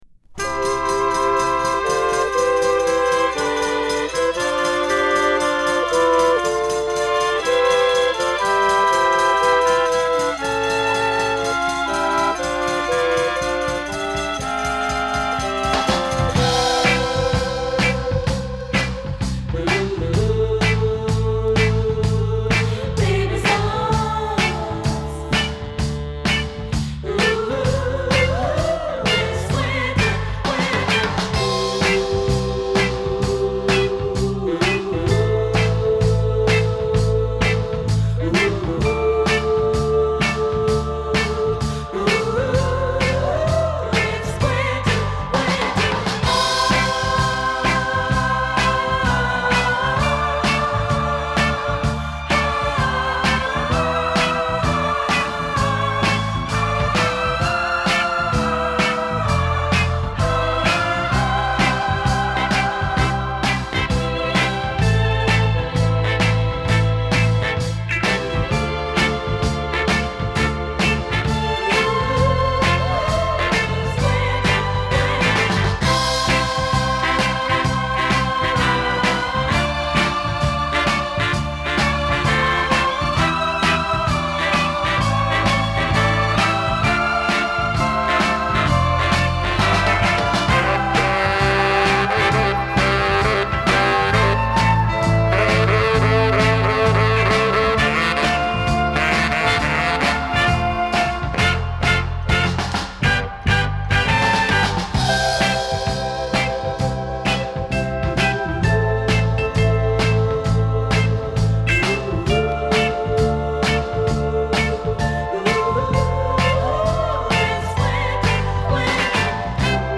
Motownマナーに則った優しく包まれるような歌声を主としたダンサブルなR&Bソウル。